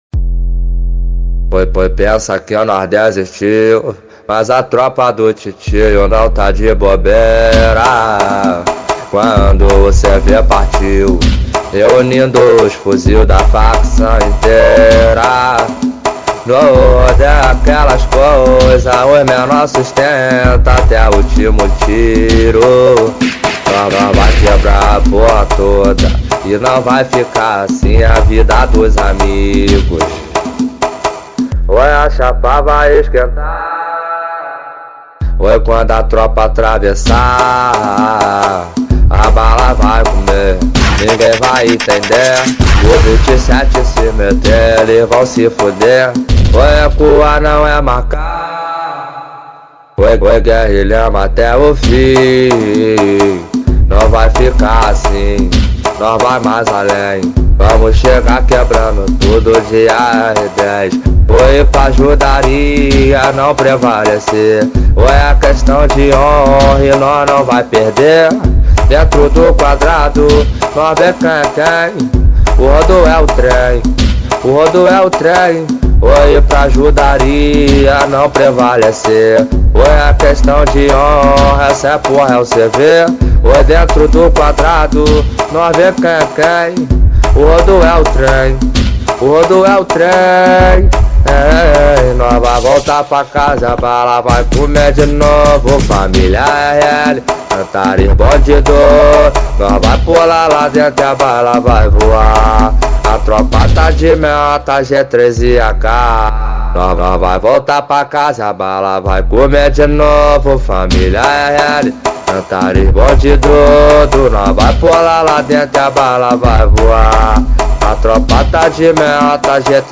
2024-06-05 12:56:32 Gênero: Funk Views